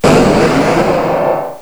cry_not_mega_blastoise.aif